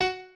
piano2_12.ogg